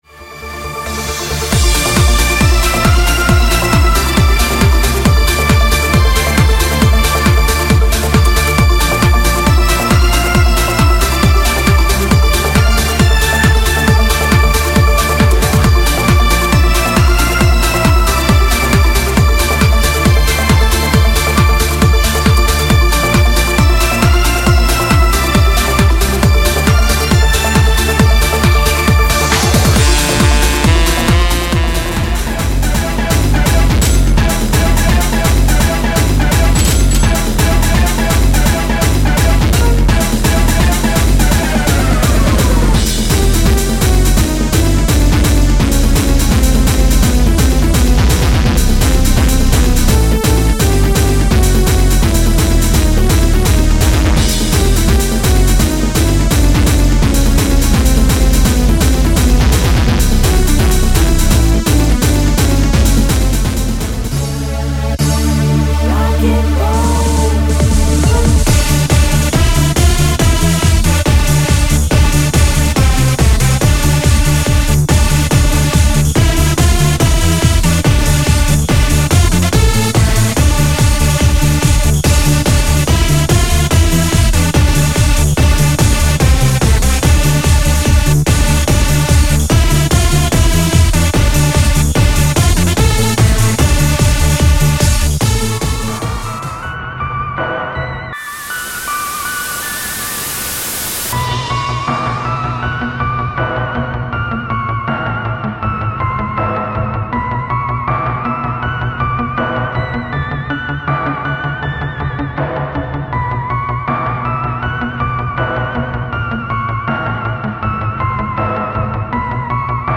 XBLA音楽アレンジCD